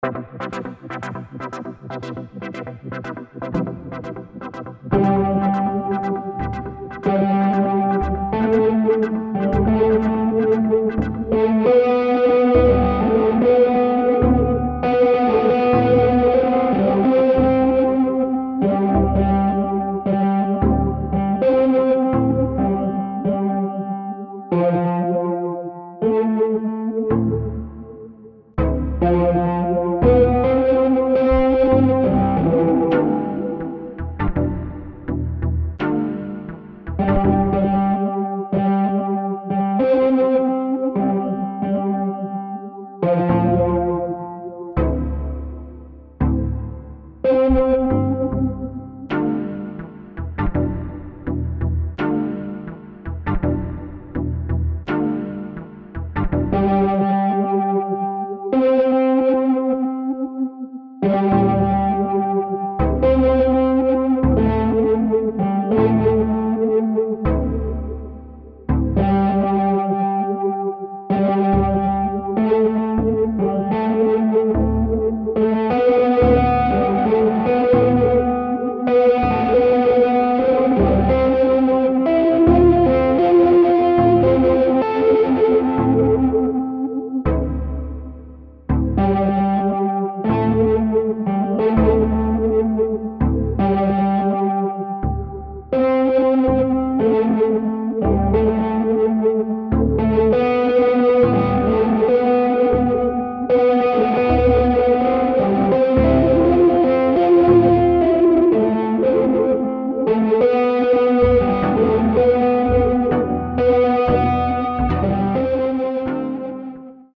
Suspens Trip hop